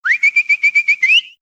call_pet.ogg